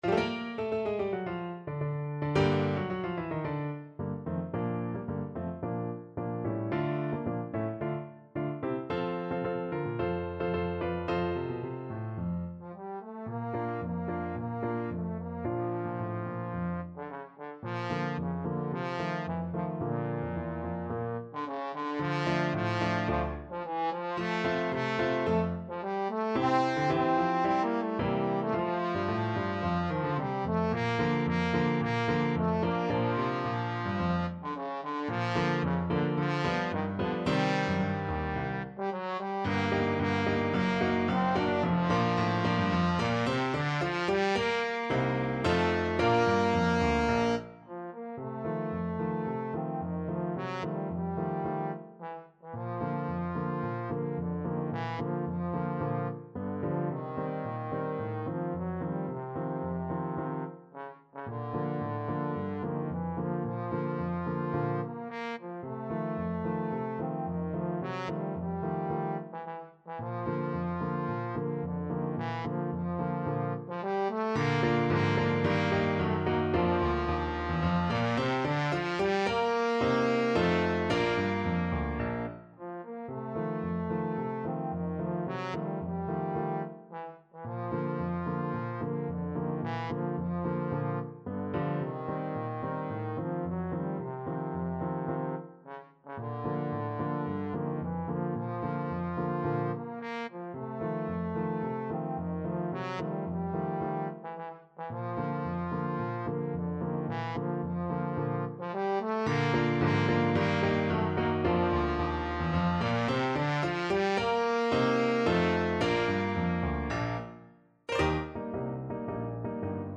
2/2 (View more 2/2 Music)
Classical (View more Classical Trombone Music)